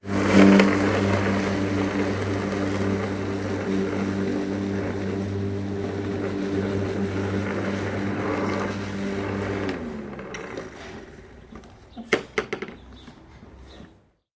工作室效果S单声道和立体声 " 00218 电动割草机 1
描述：用电动割草机割草。通过变焦H2后方47米的距离进行记录。
Tag: 切口 切割 electrin 割草机 修剪